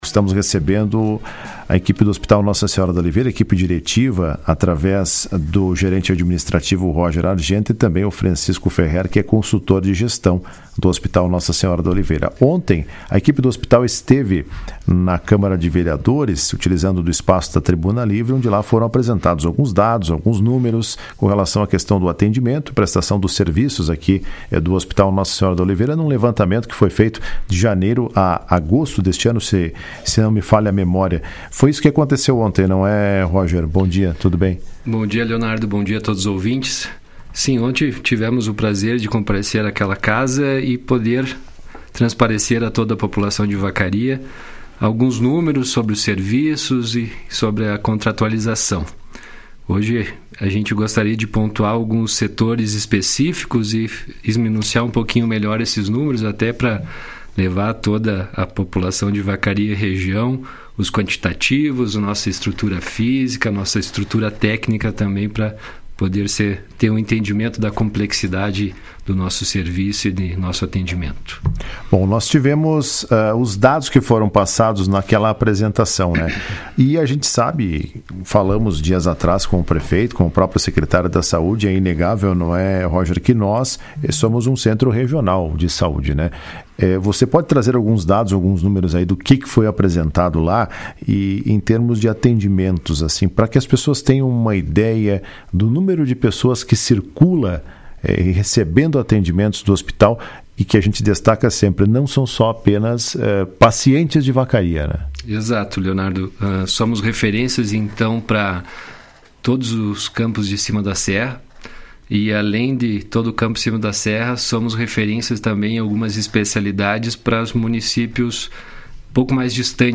Após a entrega da nova minuta de contrato do executivo municipal com o Hospital Nossa Senhora da Oliveira, a direção daquela casa de saúde participou do programa Comando Geral desta terça-feira para trazer dados sobre os atendimentos prestados pelo hospital e comentar sobre a situação da formalização do novo instrumento contratual com o município.